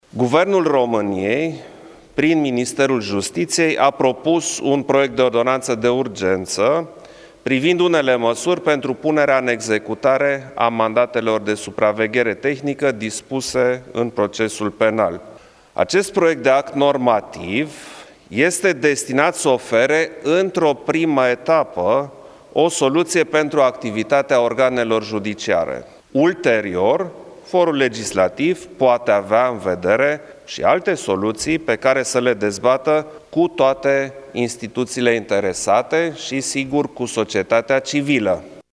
Șeful statului a subliniat că decizia Curţii Constituţionale prin care SRI nu mai poate face interceptări telefonice în dosare penale trebuie pusă în practică, iar ordonanţa de urgenţă a Guvernului urmăreşte să permită ca justiţia să-şi continue în mod eficient activitatea: